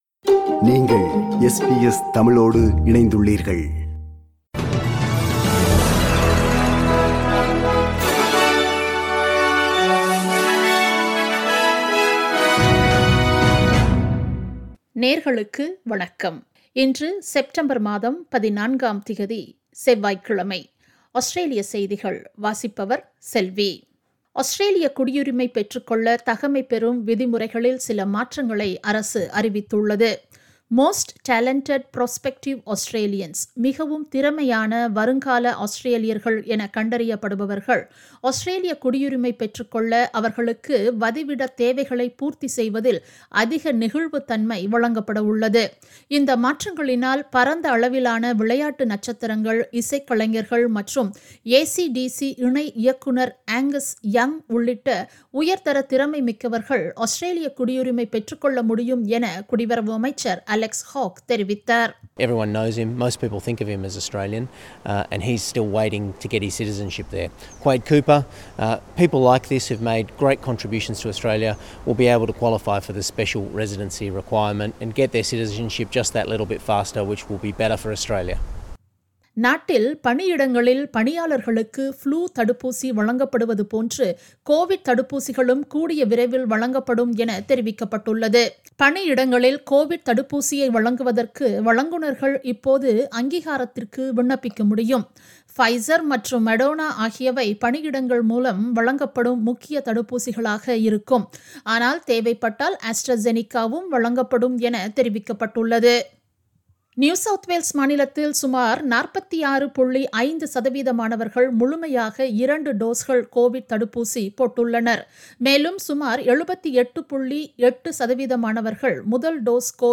Australian news bulletin for Tuesday 14 September 2021.